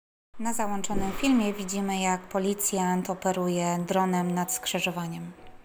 Nagranie audio audiodeskrypcja fimu